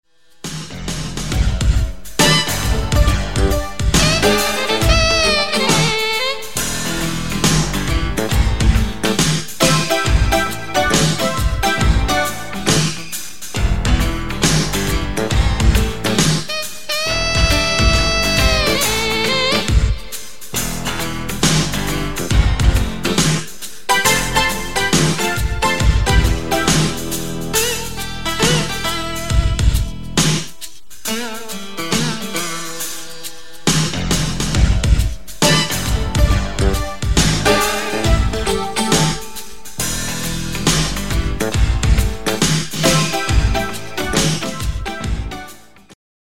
• Качество: 128, Stereo
OST